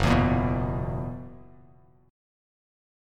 F#11 chord